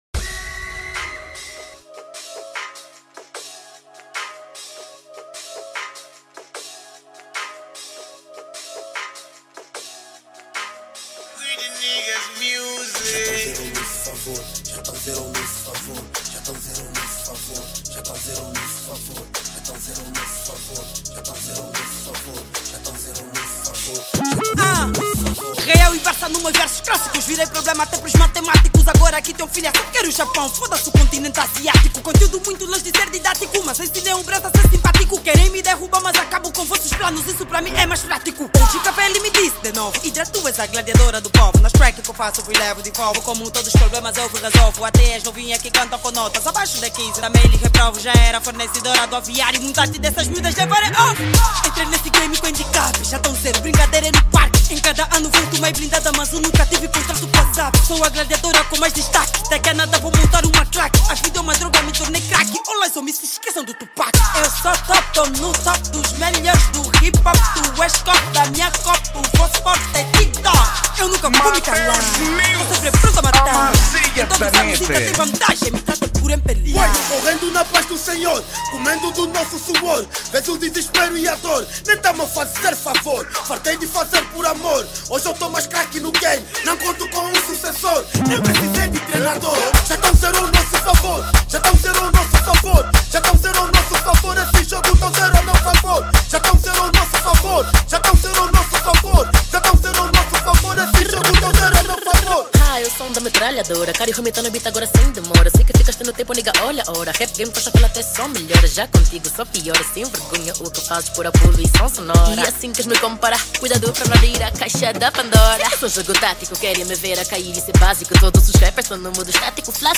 | Trap